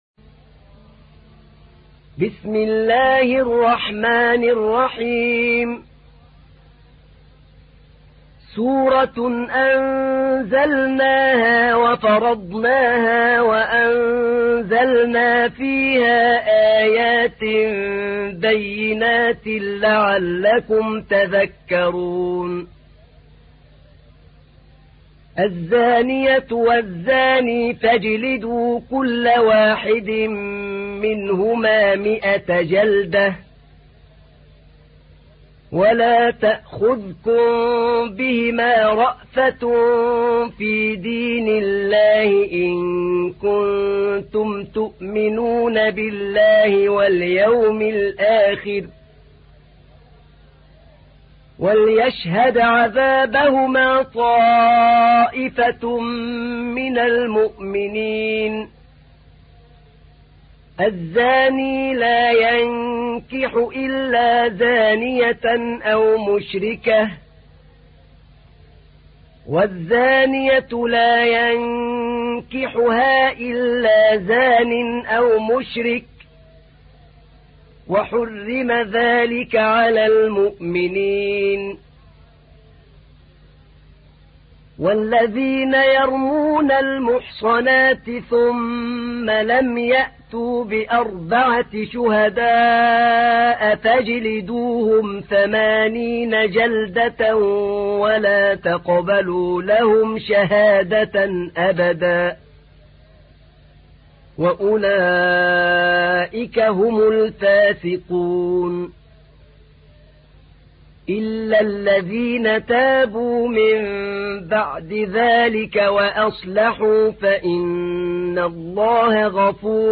تحميل : 24. سورة النور / القارئ أحمد نعينع / القرآن الكريم / موقع يا حسين